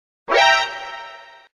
Play, download and share Metal Gear Exclamation original sound button!!!!
metal-gear-exclamation.mp3